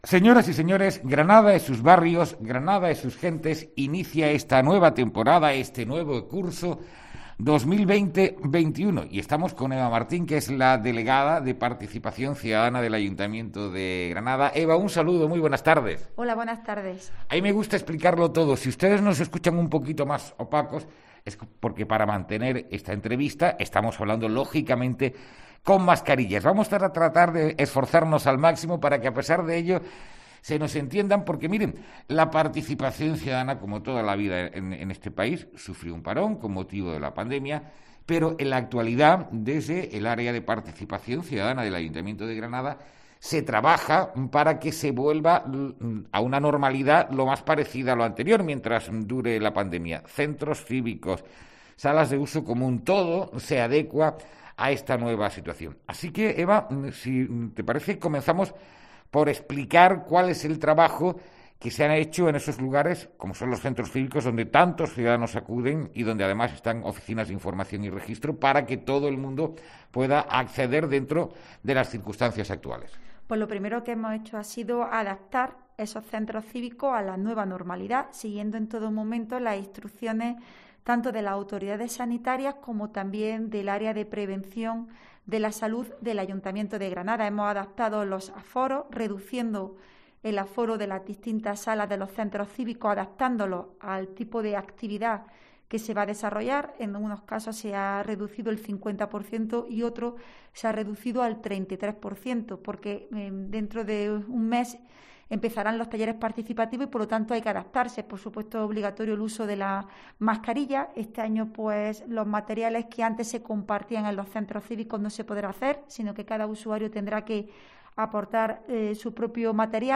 Entrevista a Eva Martín, concejala de Participación Ciudadana